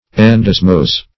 Endosmose \En"dos*mose`\, Endosmosis \En`dos*mo"sis\, n. [NL.